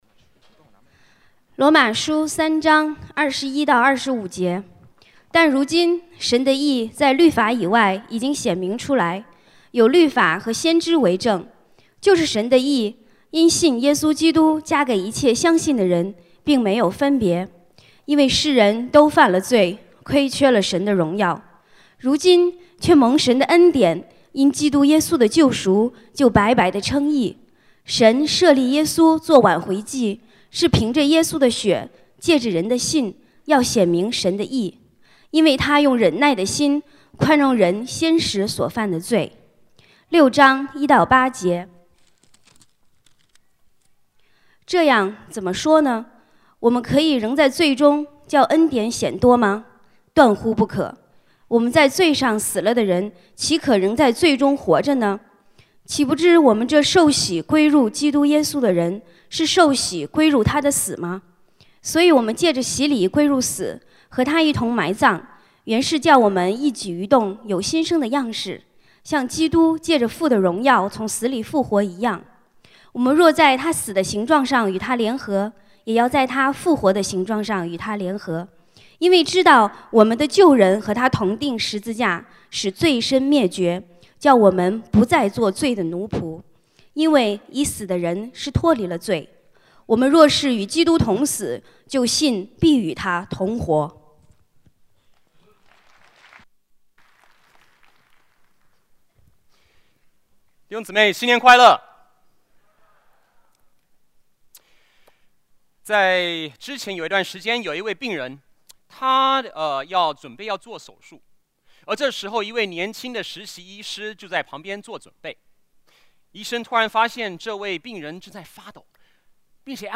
主日证道 |  全新的你